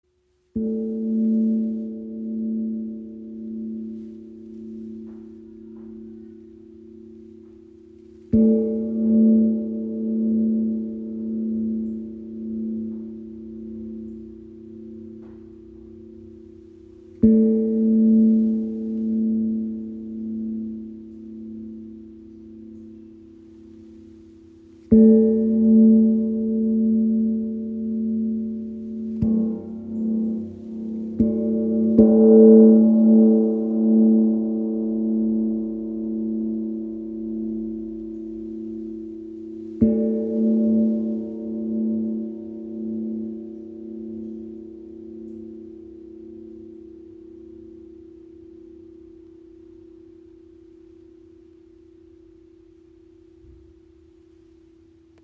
Handgefertigt von WOM Gongs in Italien – kraftvoller, klarer Klang.
Klangbeispiel
Dieser handgefertigte Edelstahl-Gong von WOM vereint Leichtigkeit mit Tiefe.
Sein voller Grundton und feine Obertöne erschaffen ein lebendiges Klangfeld, das Körper und Seele berührt.